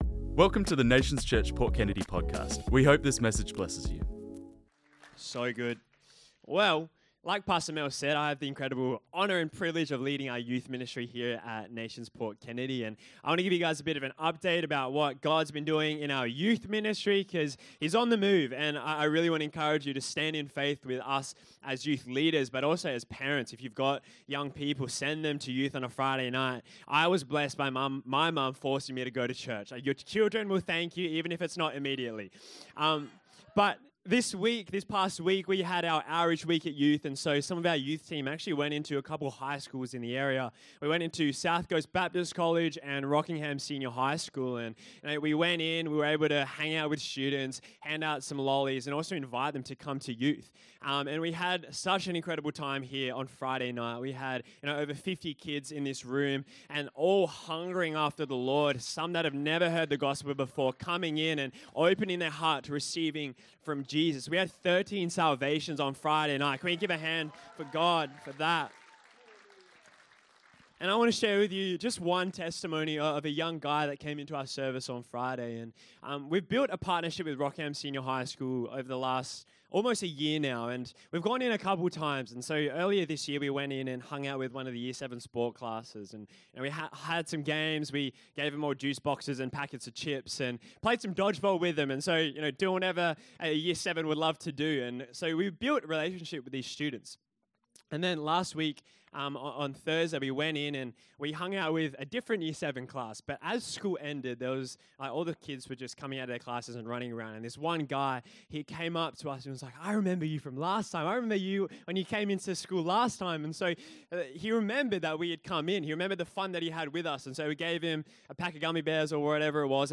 This message was preached on Sunday 22nd June 2025